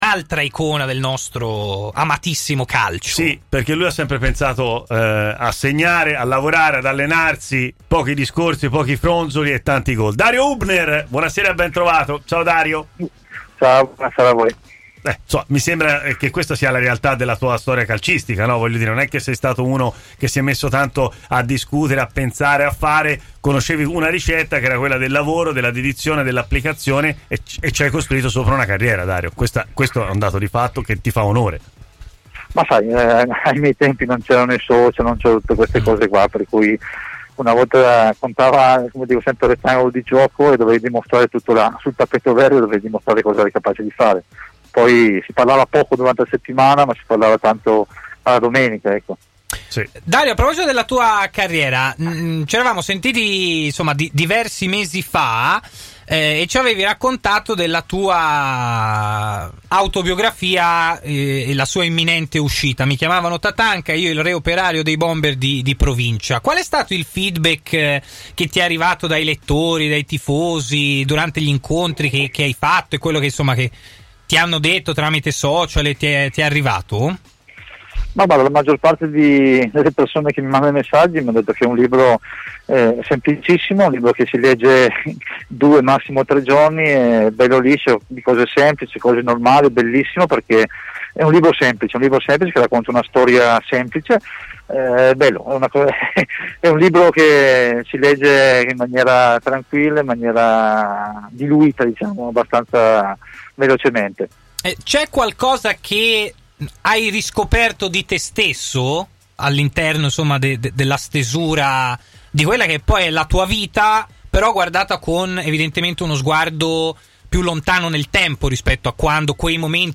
L'ex attaccante Dario Hubner è intervenuto in diretta a Stadio Aperto, trasmissione di TMW Radio